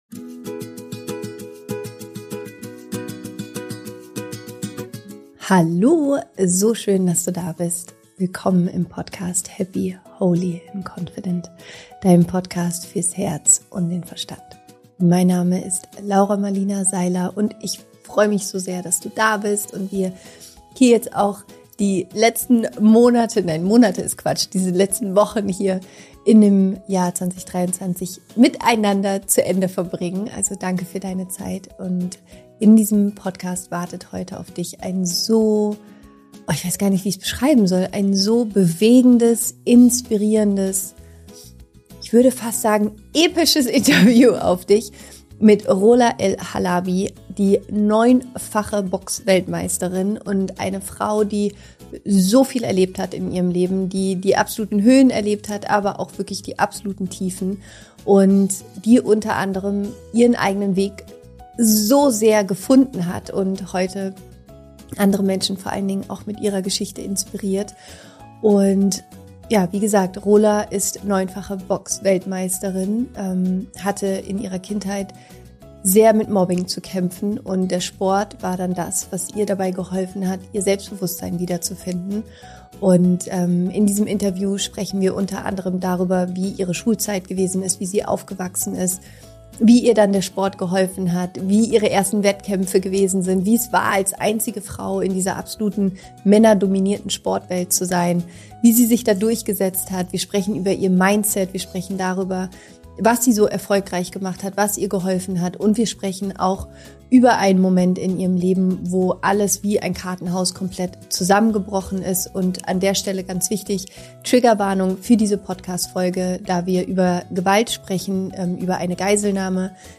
Wie du dich ins Leben zurückkämpfst und emotional heilst – Interview mit Rola El-Halabi